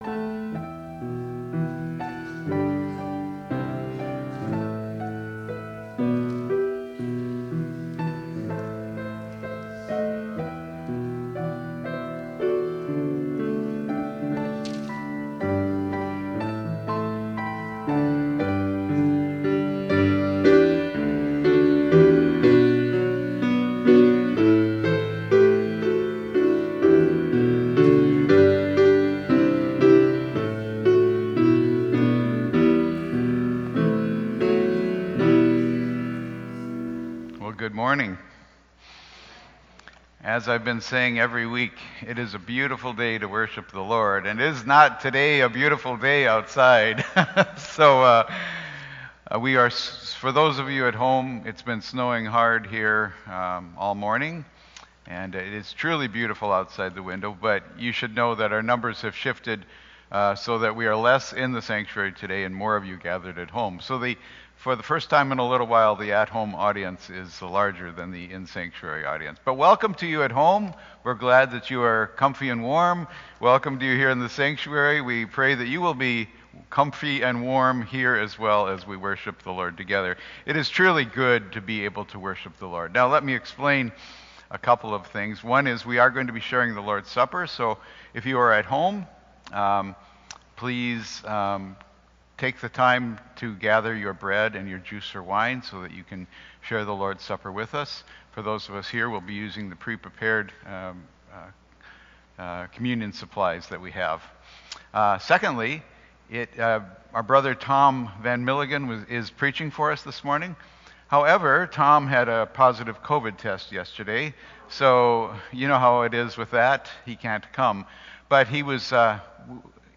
(The Lord's Supper will not be a part of the recorded worship.)